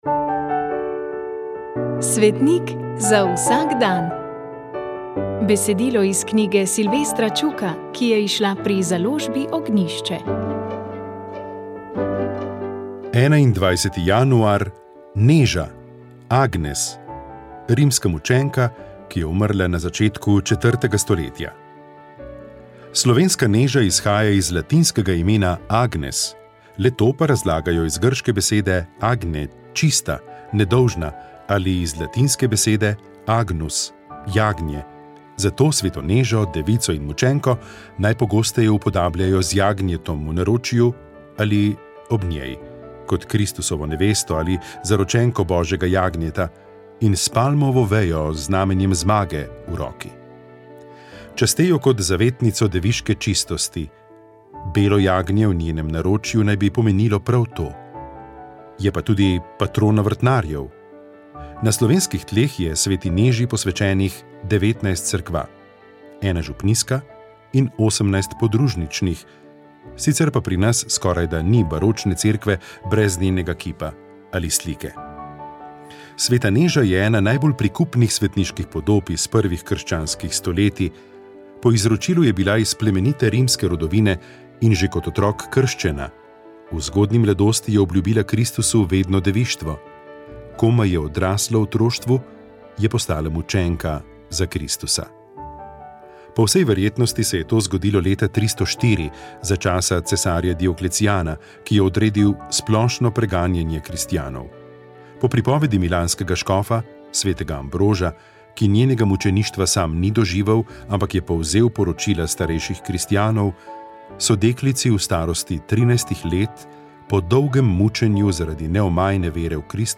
Duhovni nagovor
Na sedmo velikonočno nedeljo je duhovni nagovor pripravil ljubljanski nadškof msgr. dr. Anton Stres.